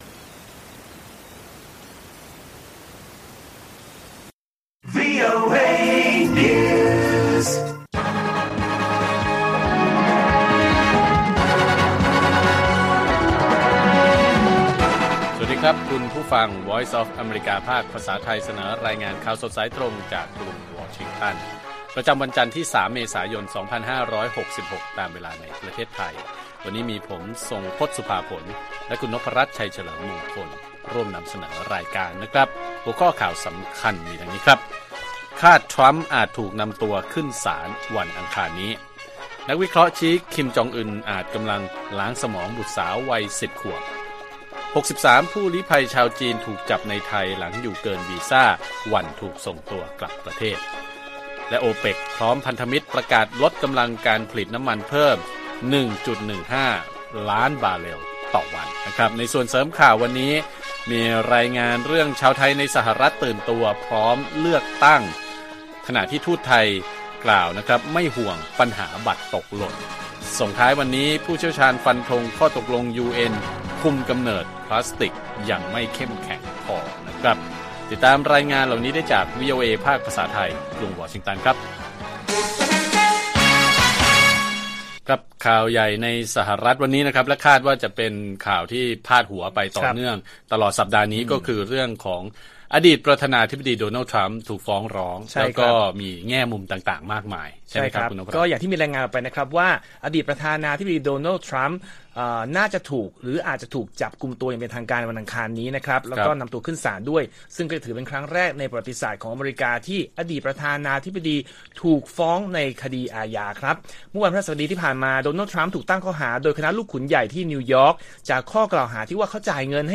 ข่าวสดสายตรงจากวีโอเอไทย จันทร์ ที่ 3 เม.ย. 66